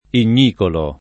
[ in’n’ & kolo ]